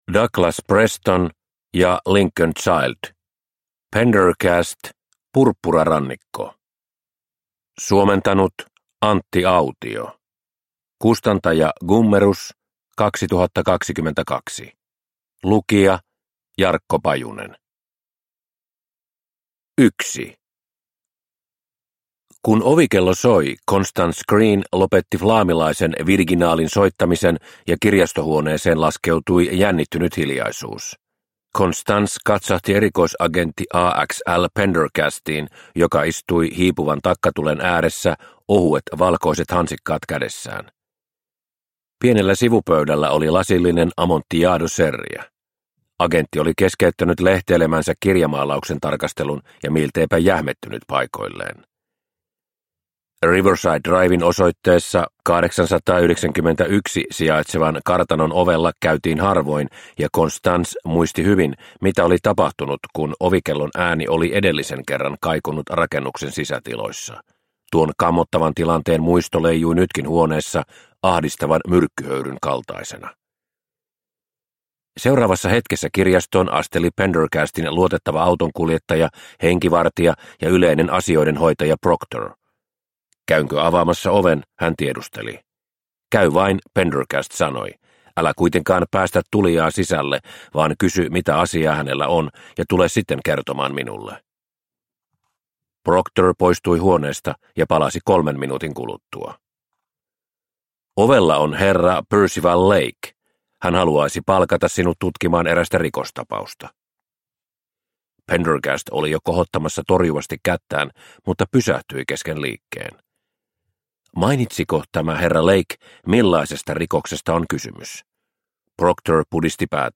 Purppurarannikko – Ljudbok – Laddas ner